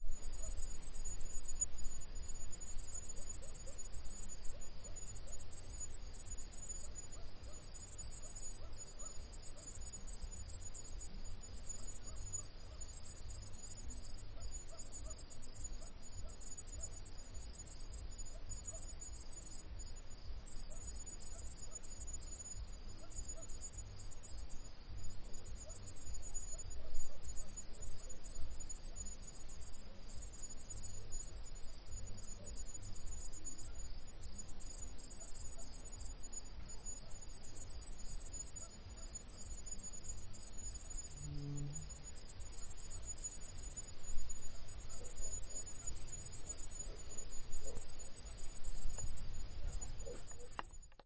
有蟋蟀的夜晚氛围
描述：夜晚与蟋蟀的氛围。
Tag: 蟋蟀夏天 周围环境